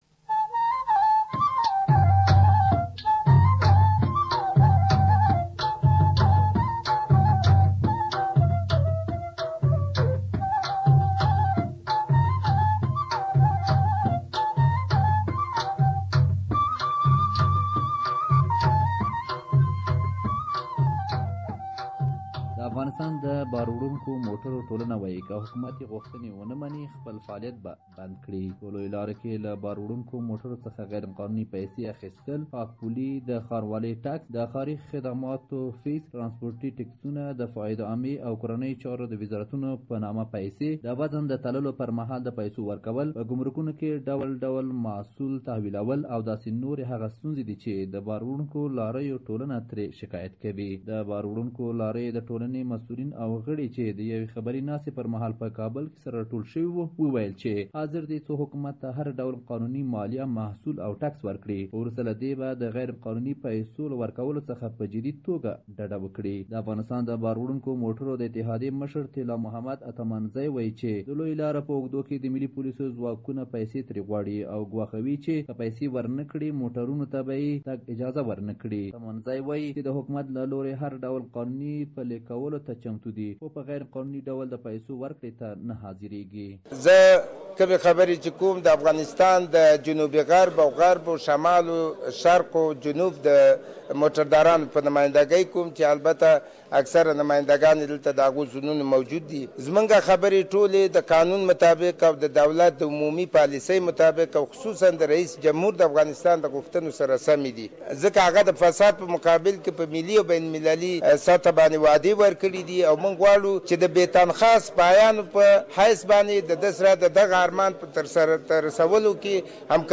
The Drivers Union says if the government doesn't come with a solution they will stop their operation. Please listen to the full report here.